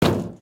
Divergent / mods / Footsies / gamedata / sounds / material / human / step / tin1.ogg